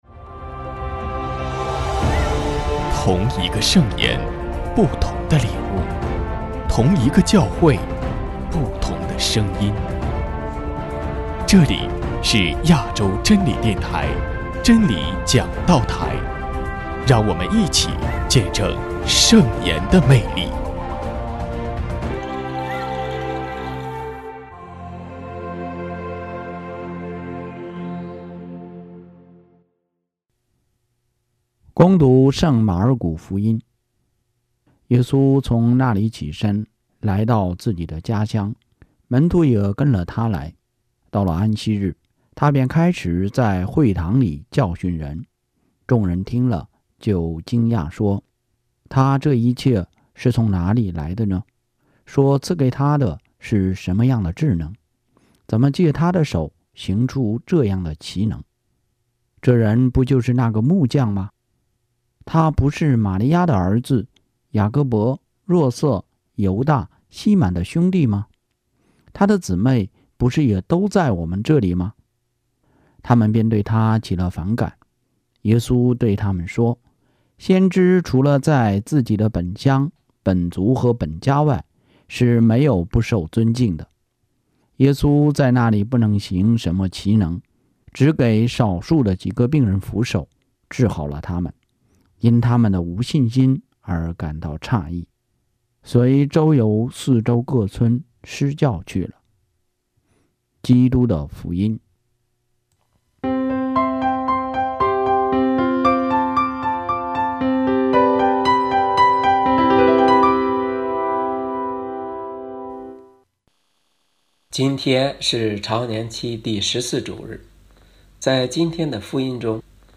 首页 / 真理讲道台/ 证道/ 乙年